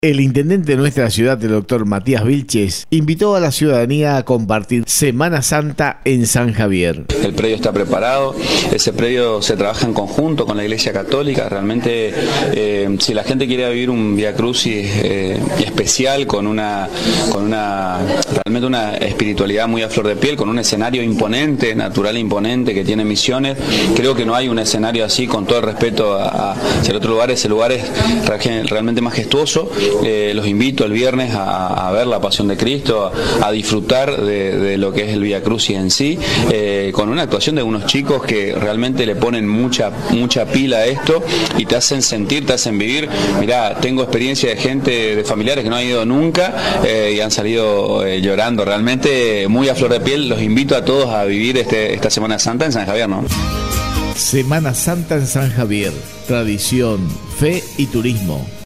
En una entrevista reciente con medios provinciales, el intendente de San Javier, Matías Vílchez, invitó a la población a visitar el Cerro Monje durante la Semana Santa, destacando la preparación especial que se ha realizado en el predio para recibir a los visitantes.